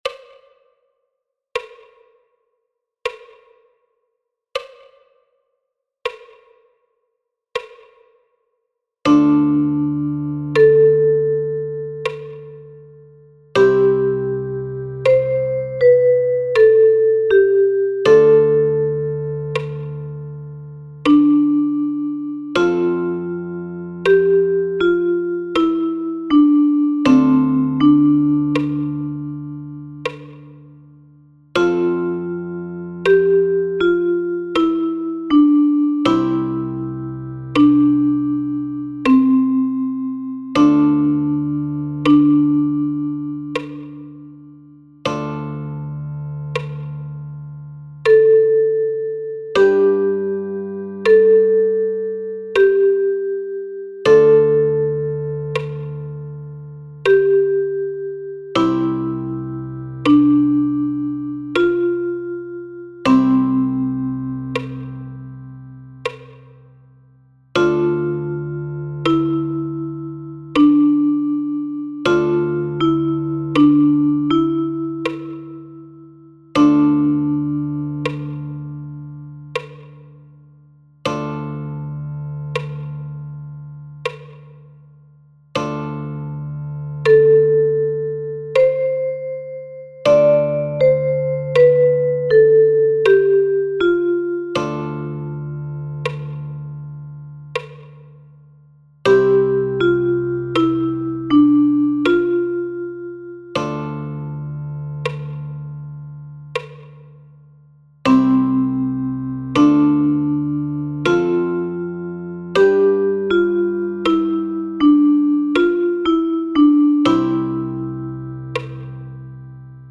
Für Sopran- oder Tenorblockflöte in barocker Griffweise.